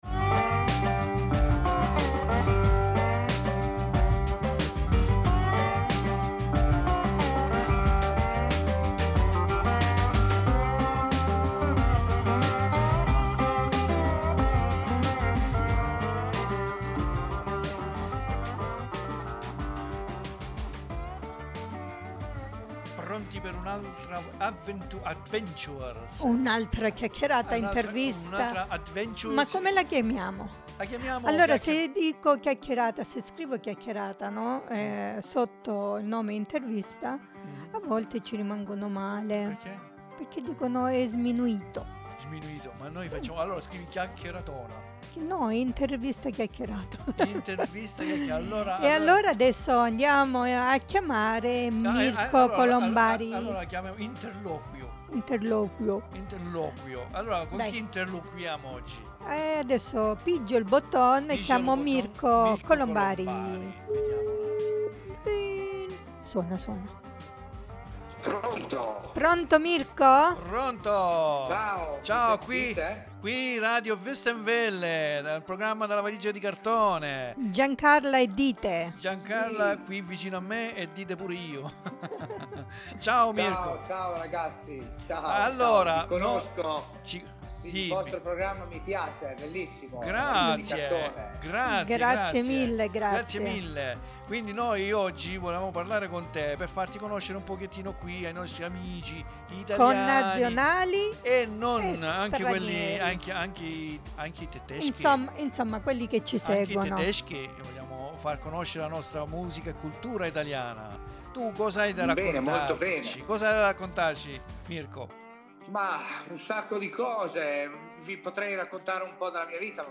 VI AUGURO UN BUON ASCOLTO DELL'INTERVISTA INTEGRALE .